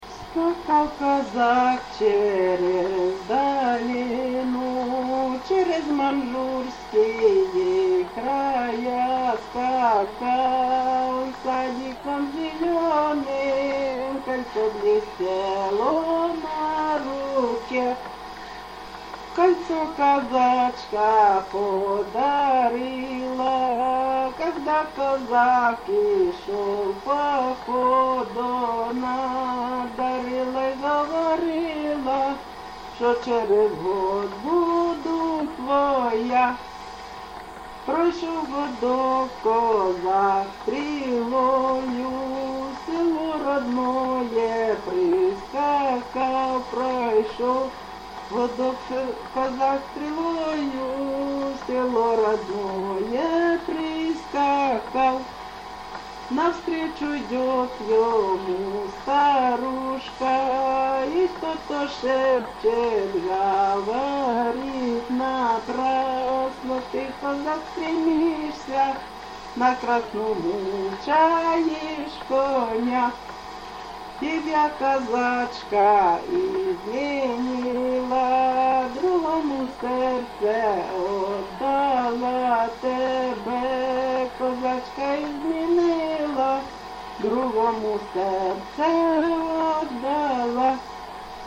ЖанрПісні з особистого та родинного життя
Місце записус. Олександро-Калинове, Костянтинівський (Краматорський) район, Донецька обл., Україна, Слобожанщина